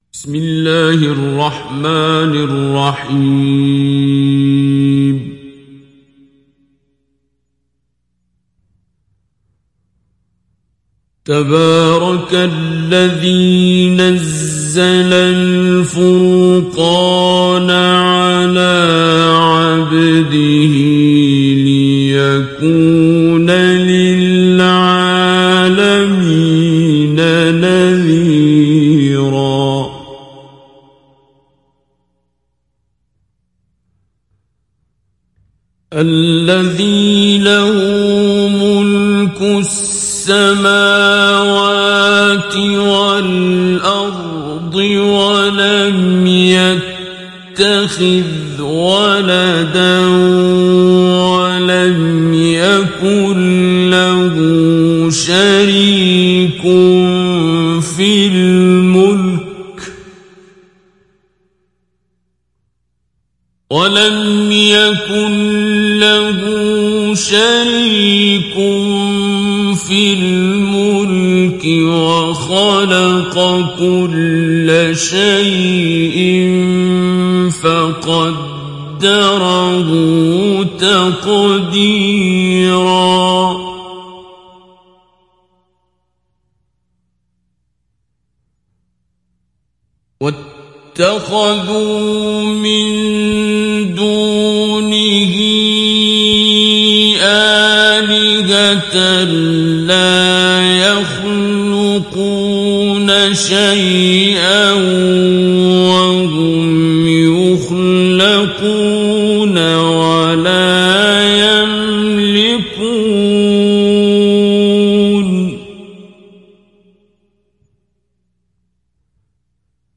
Download Surat Al Furqan Abdul Basit Abd Alsamad Mujawwad